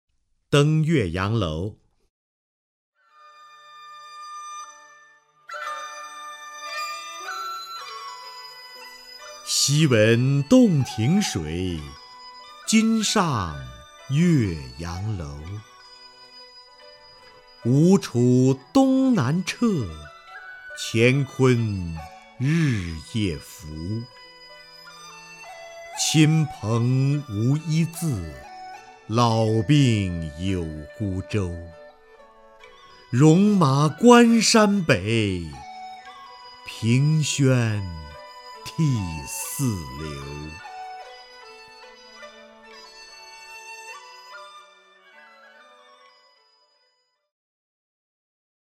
瞿弦和朗诵：《登岳阳楼》(（唐）杜甫) (右击另存下载) 昔闻洞庭水，今上岳阳楼。
名家朗诵欣赏